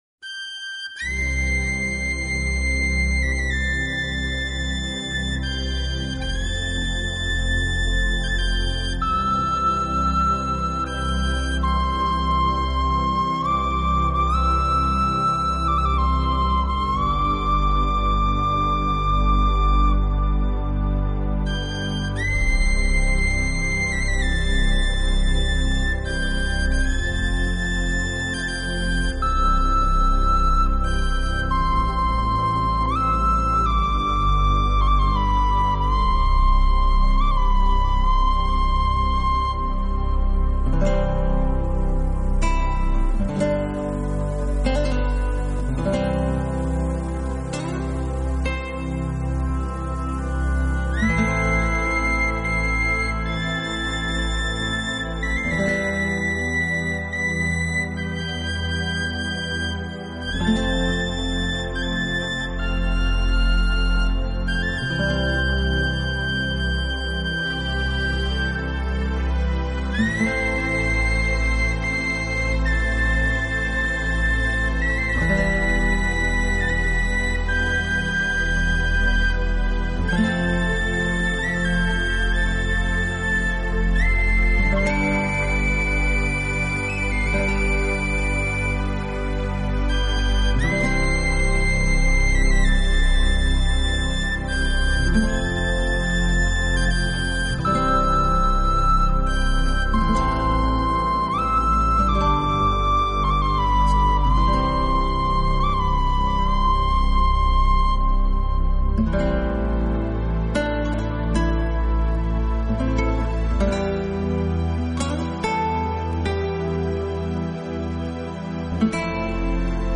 恬淡无争的田园岁月中不时飘来忧郁空灵的爱尔兰风笛...